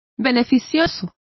Complete with pronunciation of the translation of beneficial.